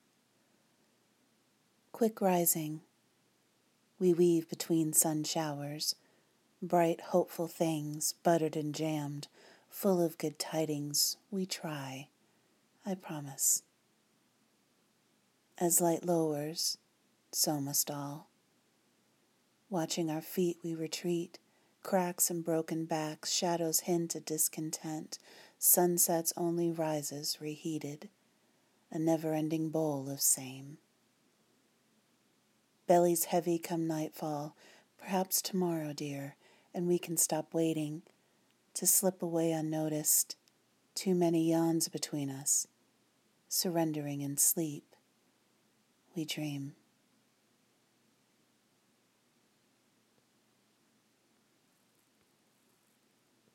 Fake Yawn